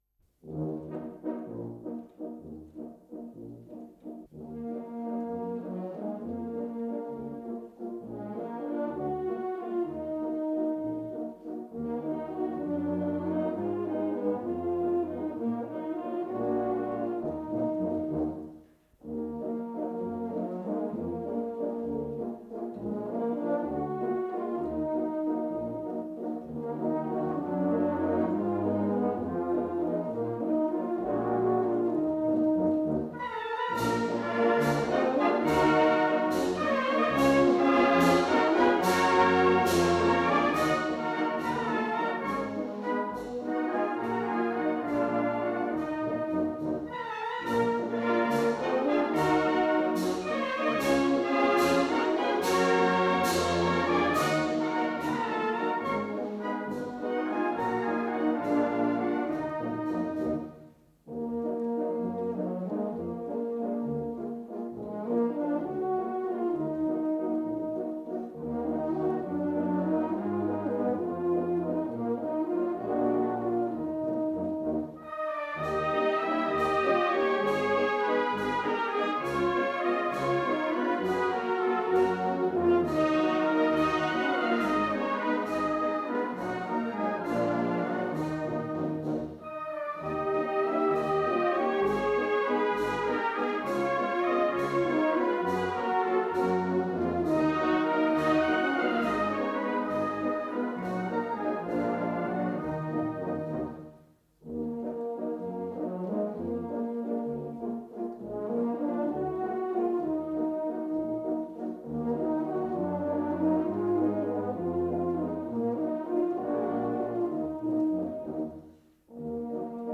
На сопках Маньчжурии. Старинный вальс